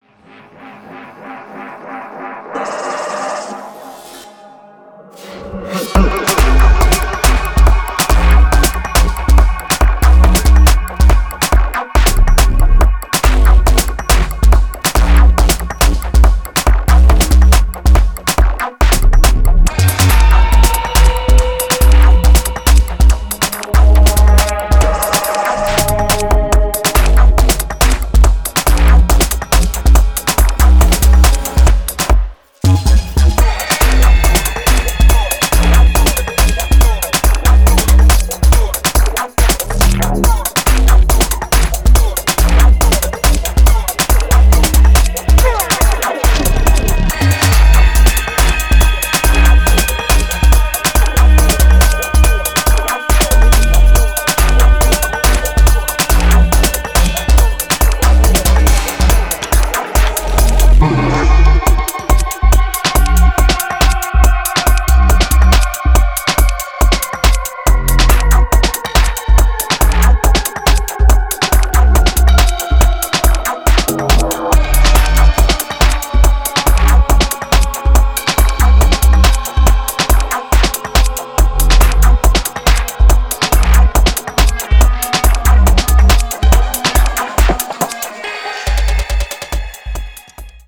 バウンシーなキックと不穏なサウンドスケープが絶妙なテンションの140BPM